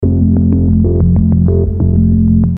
MOOGLOOP Decoded.wav